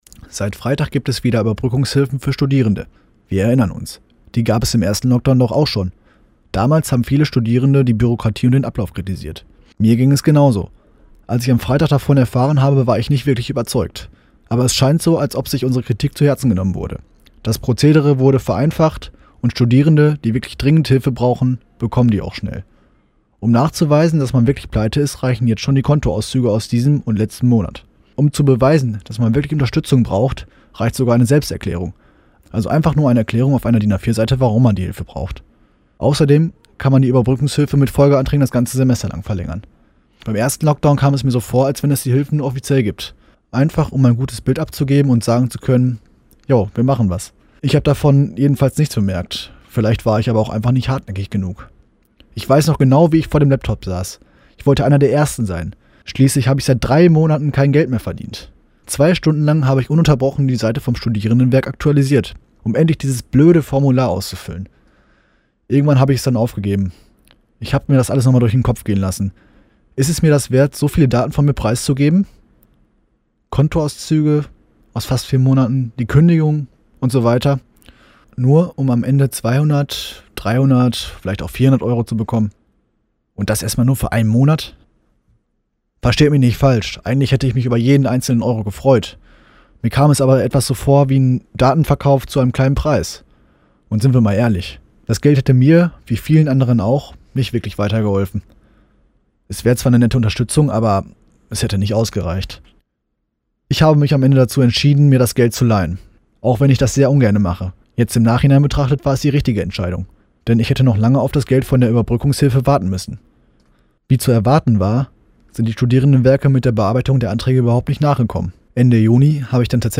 Kommentar zu den neuen Überbrückungshilfen für Studierende